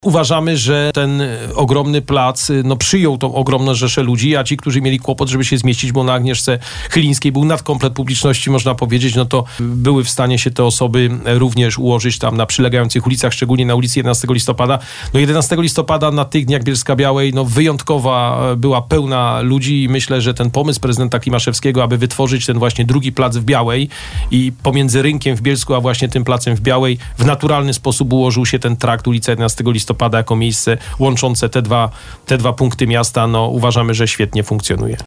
W trakcie porannej rozmowy, zastępcę prezydenta miasta, pytaliśmy o spostrzeżenia i ocenę tego, jak plac zafunkcjonował w trakcie organizacji imprezy masowej.